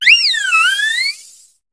Cri de Nymphali dans Pokémon HOME.